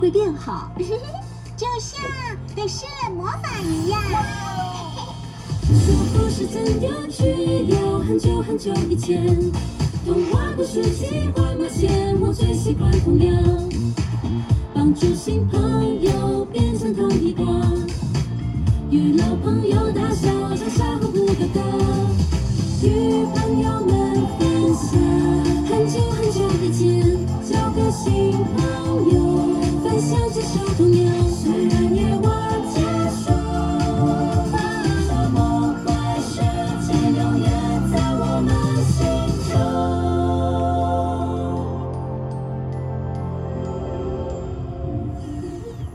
Female
Singing